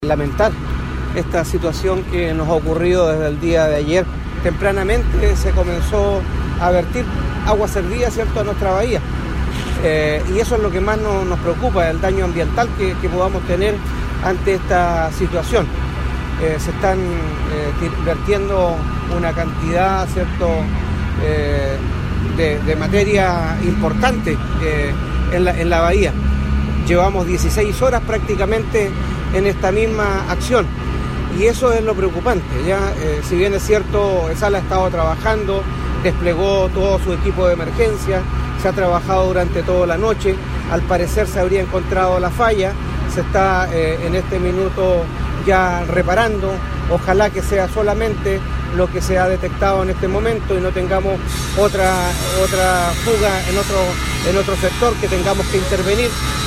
Las declaraciones las realizó la mañana de este miércoles, desde el lugar donde se tuvo que intervenir el remodelado paseo público, para encontrar la tubería que se rompió este martes y derramó miles de litros de aguas servidas al mar.